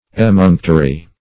Emunctory \E*munc"to*ry\, n.; pl. Emunctories. [L. emunctorium